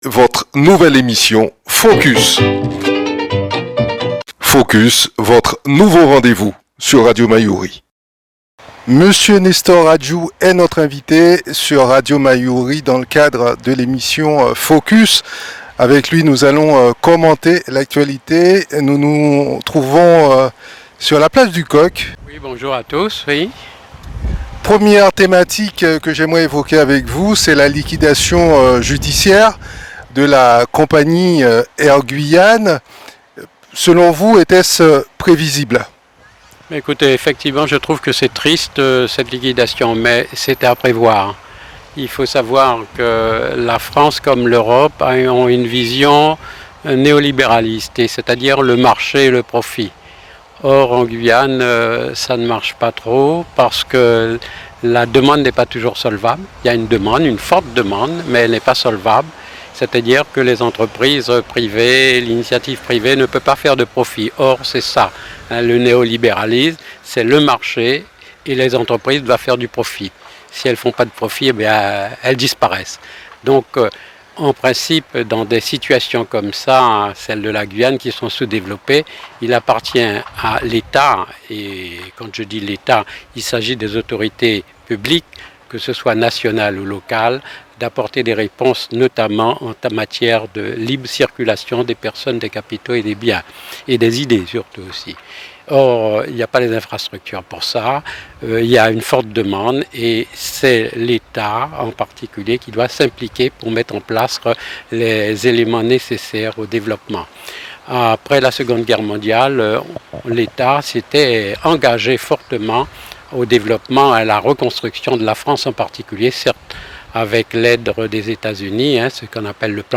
Il était notre invité dans la rubrique "FOCUS", ce mardi 18 octobre 2023 sur Radio Mayouri, à 8h30 puis rediffusée à 13h10 et 19h10.